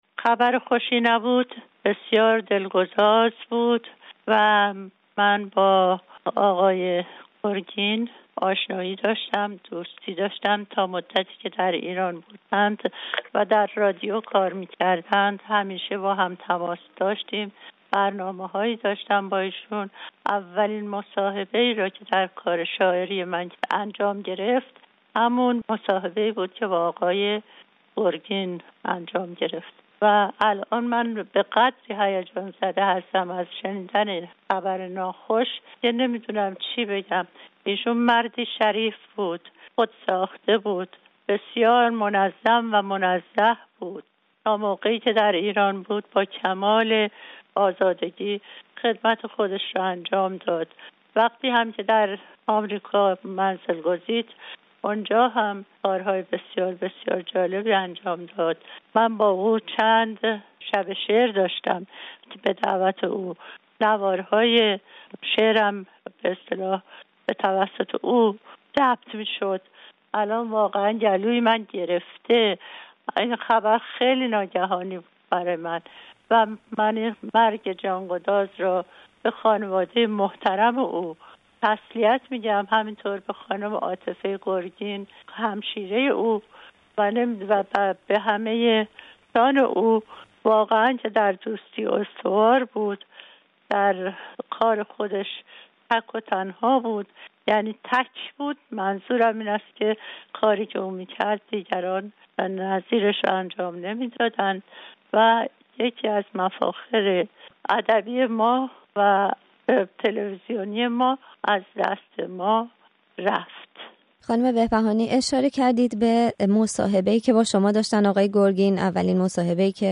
گرگین و هنر در رسانه؛ گفت‌وگو با سیمین بهبهانی